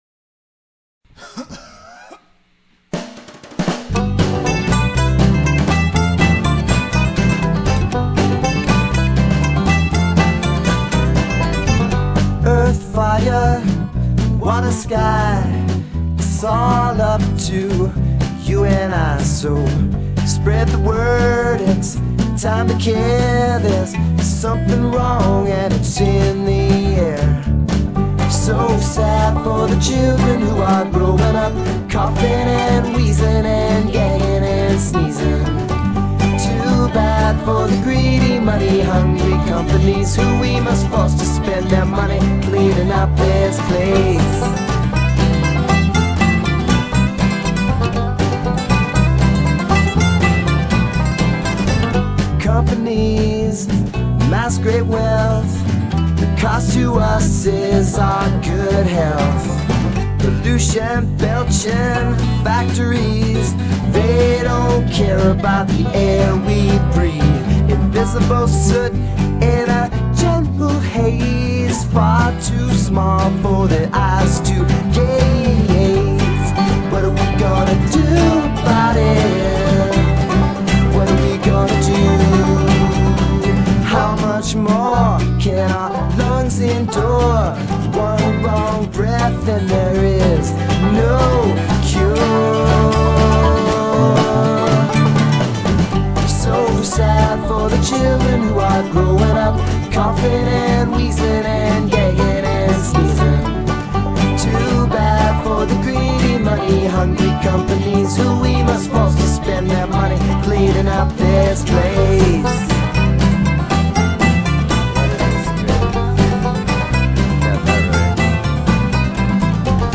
Acoustic Guitar, Vocals
Bass, Banjo, Mandolin, Percussion